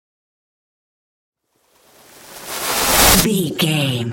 Trailer raiser
Sound Effects
Video Game Trailer
Epic / Action
Fast paced
In-crescendo
Atonal
bouncy
driving
intense
riser